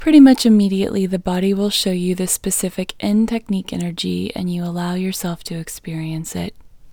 LOCATE IN English Female 35